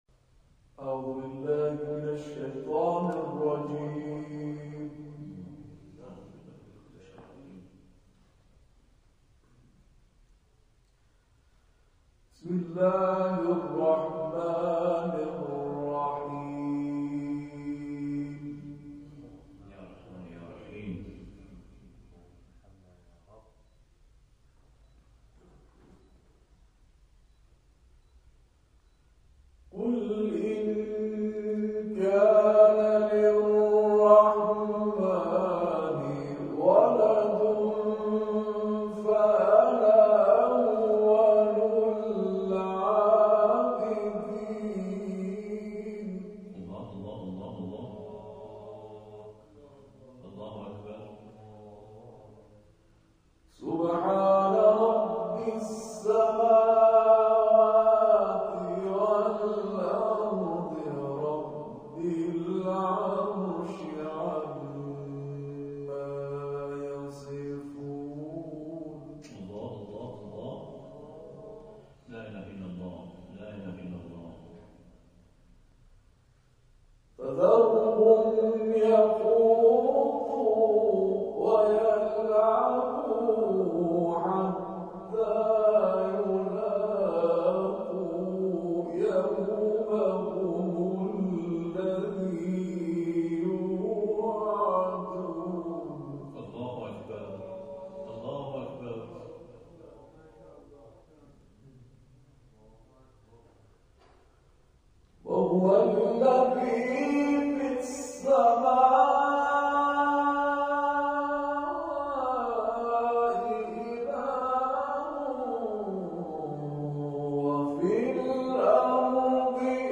در مسجد حاج نایب واقع در میدان بهارستان، چهارراه سرچشمه برگزار شد
تلاوت